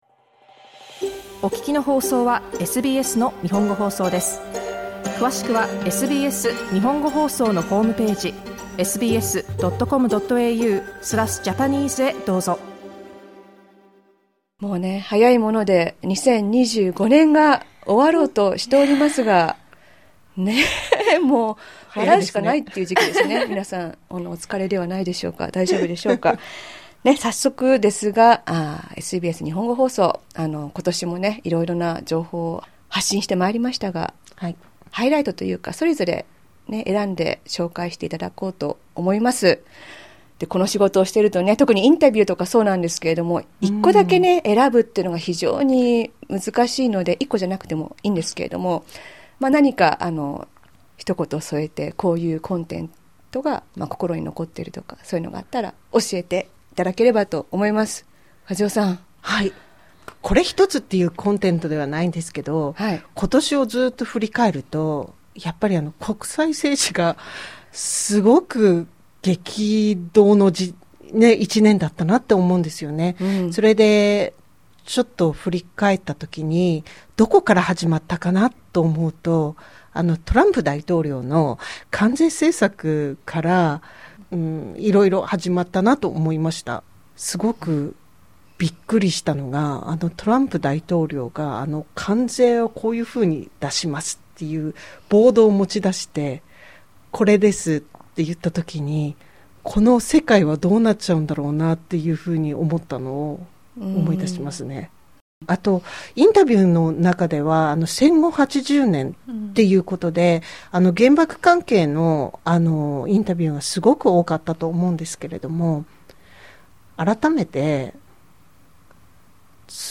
SBS Japanese's Sydney team looks back on memorable content from 2025 in a casual chat. Tune in to hear about the 80th anniversary of the end of WWII, the achievements of Japanese footballers, and our Gold Coast broadcast commemorating SBS's 50th anniversary.